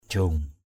/ʥio:ŋ/ (đg.) dồn, tụ tập. ikan jiong di kraong kayua njâc ikN _j`U d} _k” ky&% W;C cá tụ ở sông do sông nhiều rong rêu (đất lành chim đậu).